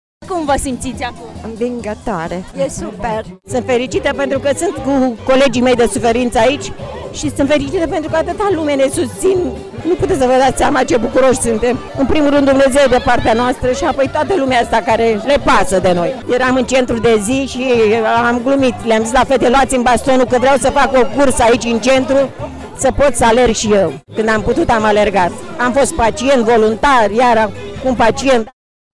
Cea mai aplaudată a fost, însă, Cursa Speranței, de 900 de metri, în care au participat doar pacienți HOSPICE Casa Speranței: